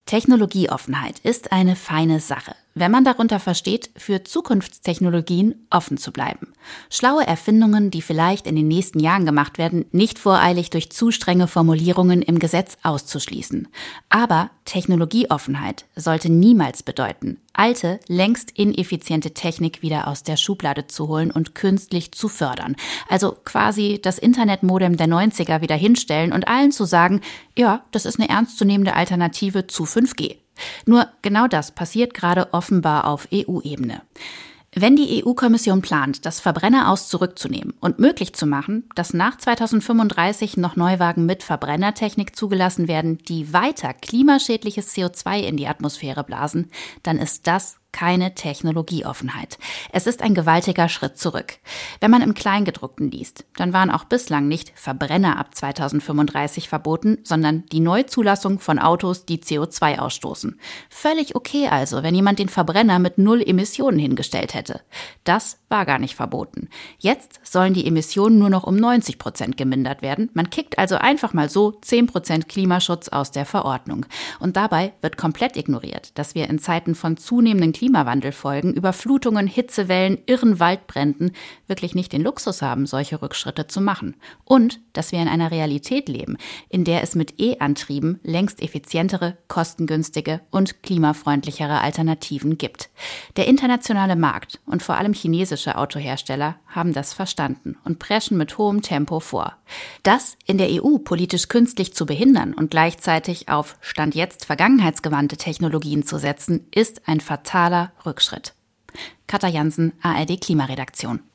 Meinung: Die Abkehr vom Verbrenner-Aus - ein fataler Rückschritt